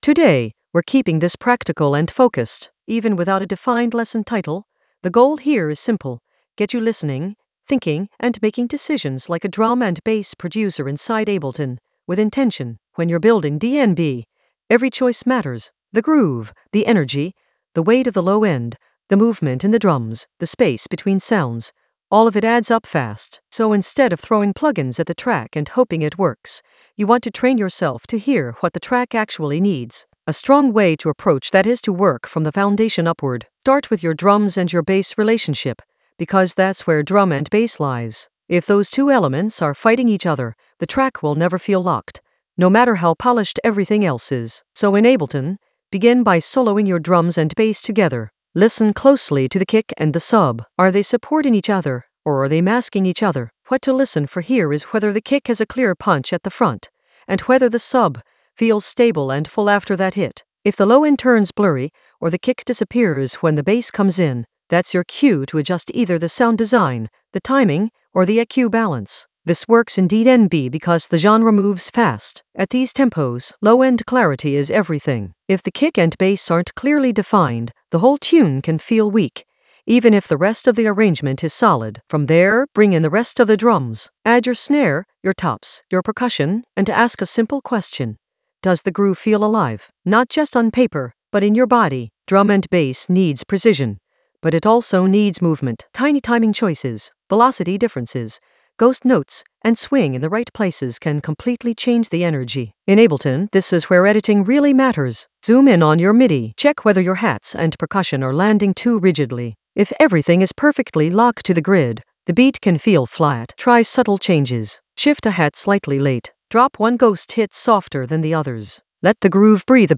An AI-generated intermediate Ableton lesson focused on Fabio sub basslines that shake in the Basslines area of drum and bass production.
Narrated lesson audio
The voice track includes the tutorial plus extra teacher commentary.